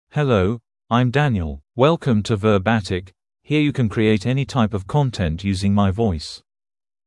Daniel — Male English (United Kingdom) AI Voice | TTS, Voice Cloning & Video | Verbatik AI
MaleEnglish (United Kingdom)
Daniel is a male AI voice for English (United Kingdom).
Voice sample
Daniel delivers clear pronunciation with authentic United Kingdom English intonation, making your content sound professionally produced.